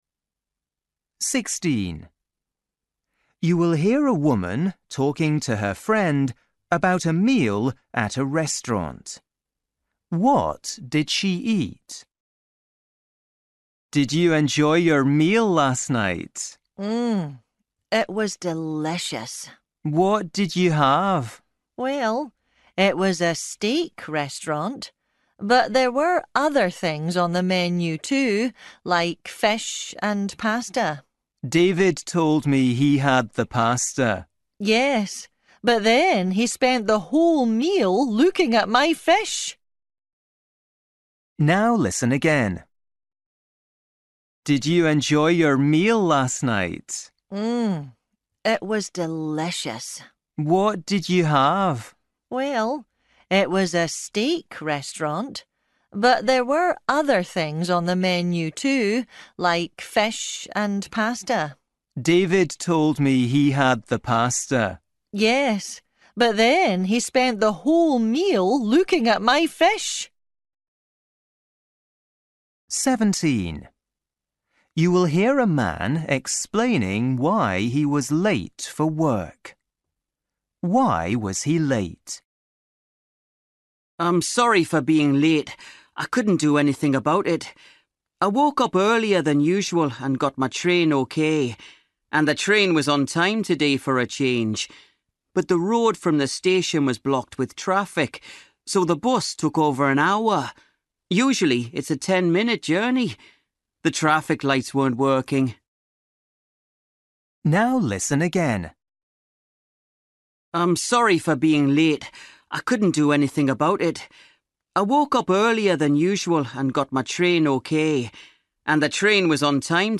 Listening: everyday short conversations
16   You will hear a woman talking to her friend about a meal at a restaurant. What did she eat?
17   You will hear a man explaining why he was late for work. Why was he late?
19   You will hear two friends talking about shopping. What do they need from the supermarket?